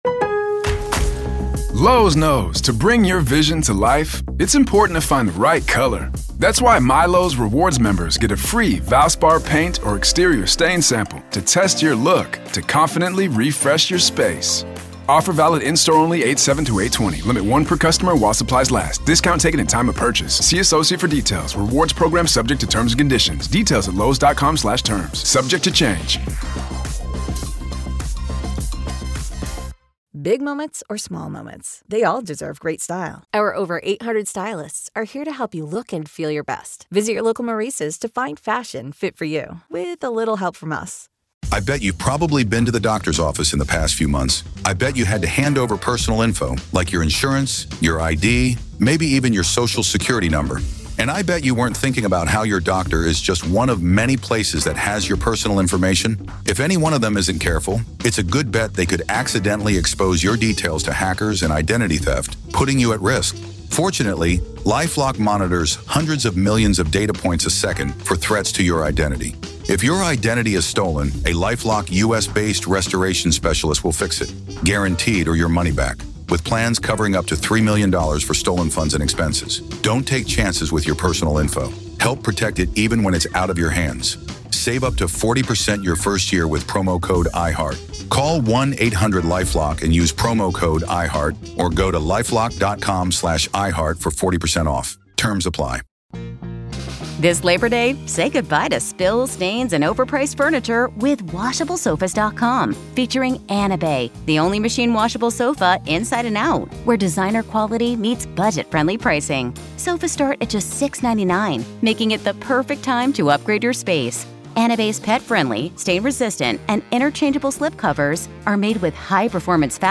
Each episode navigates through these stories, illuminating their details with factual reporting, expert commentary, and engaging conversation.